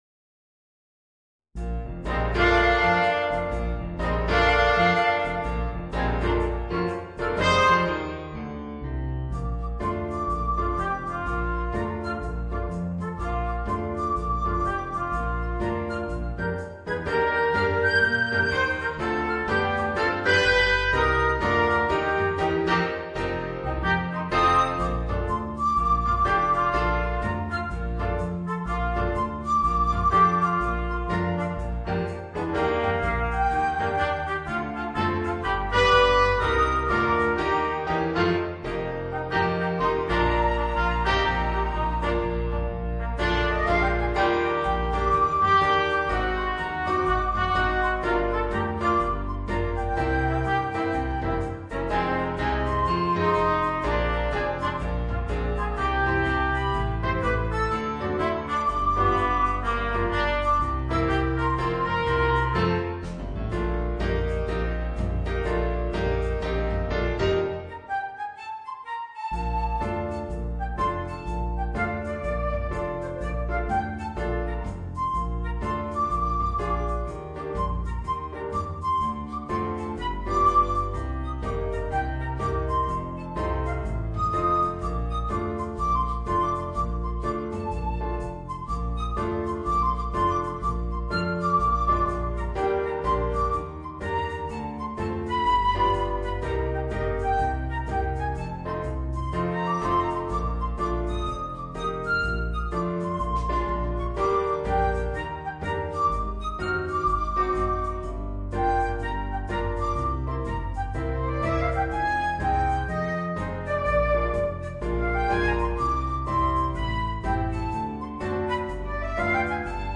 Flute, Trumpet w/ Audio